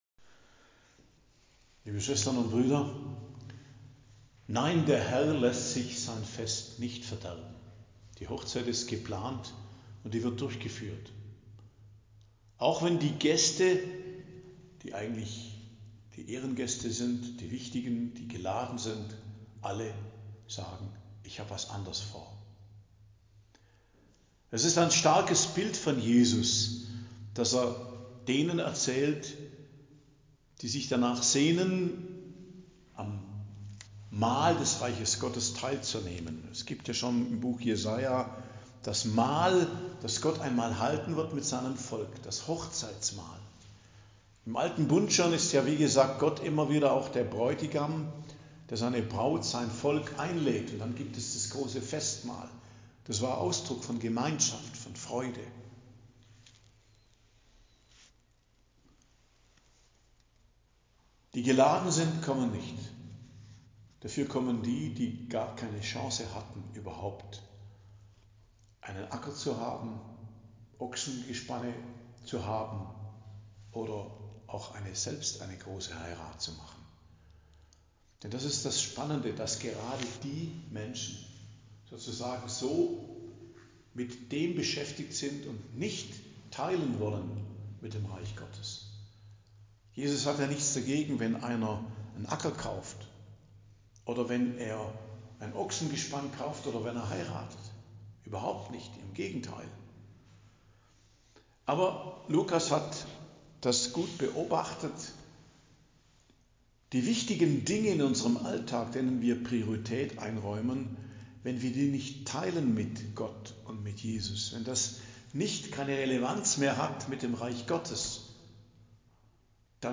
Predigt am Dienstag der 31. Woche i.J. 5.11.2024